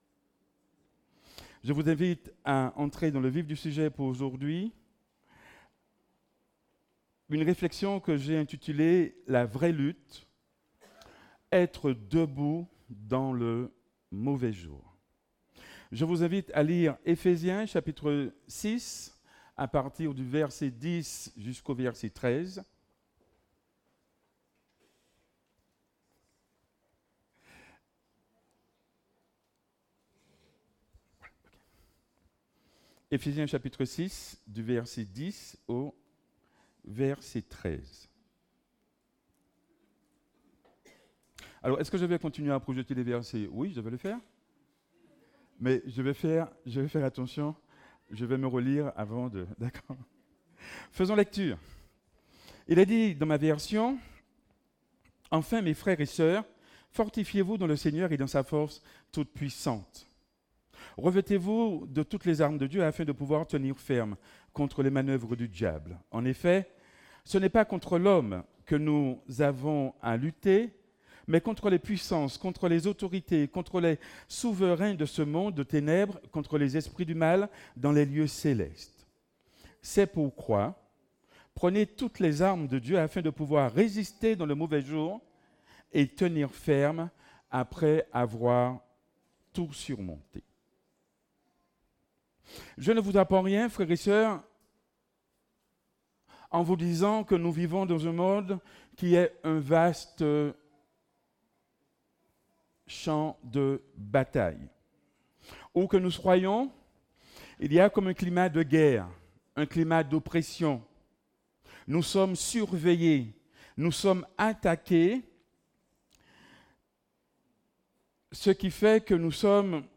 Rester debout dans le mauvais jour Prédicateur